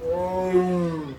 animalworld_tiger.ogg